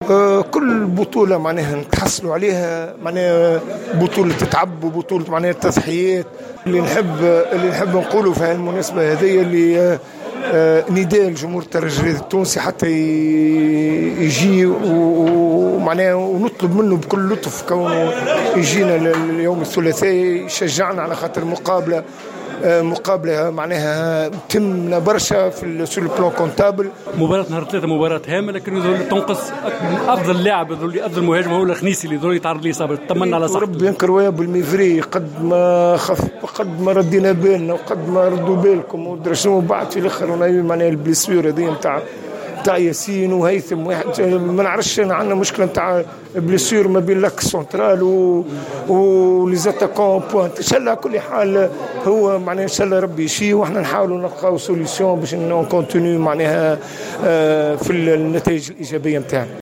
خالد بن يحي : مدرب الترجي الرياضي